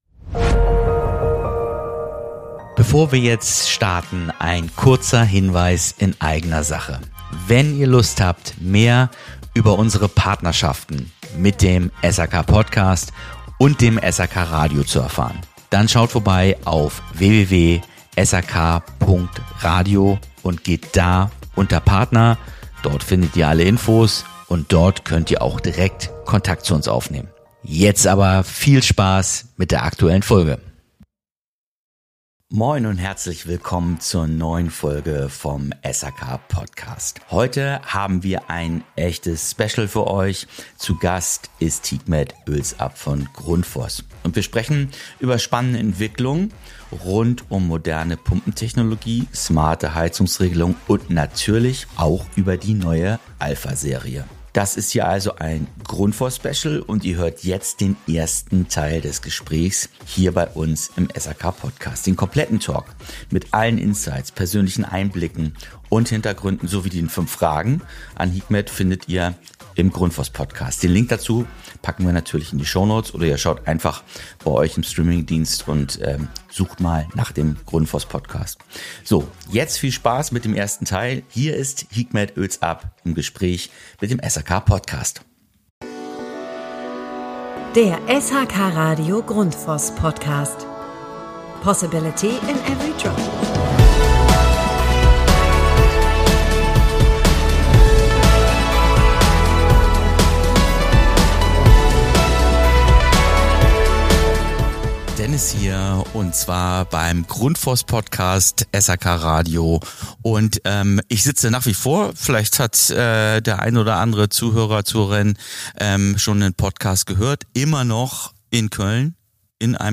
aufgenommen in einem ganz besonderen Setting: einem Kinosaal in Köln, direkt vor einem großen Branchen-Event.